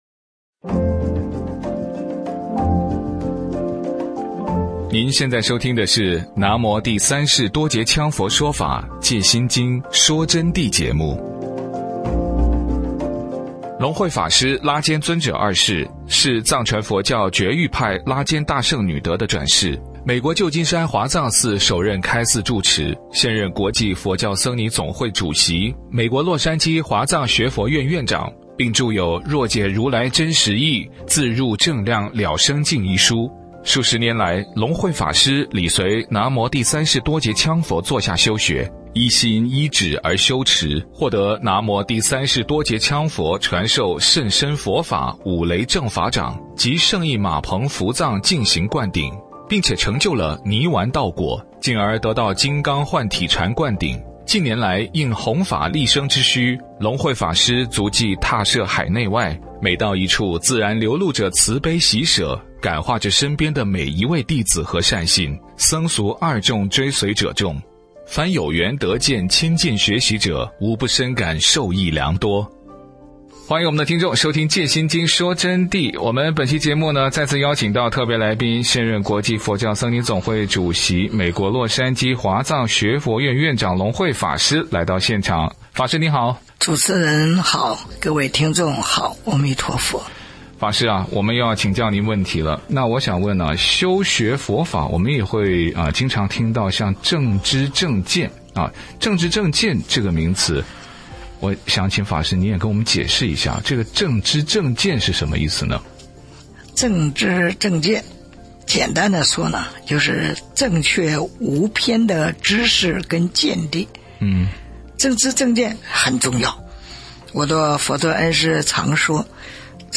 佛弟子访谈（三十九）什么是正知正见？佛陀亲说法音有多稀有难得？